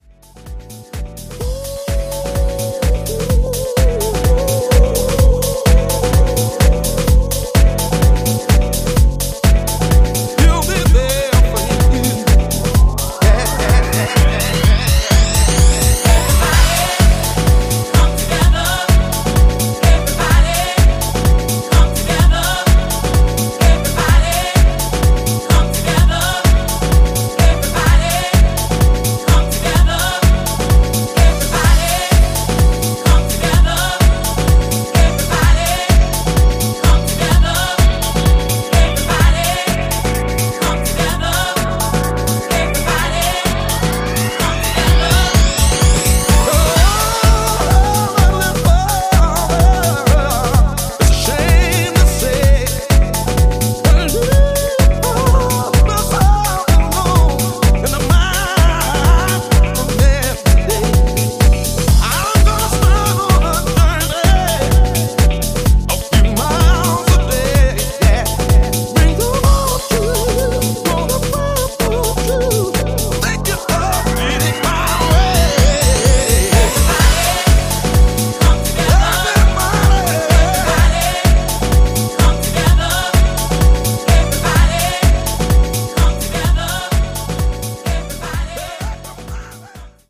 vocal-led, groove-driven house music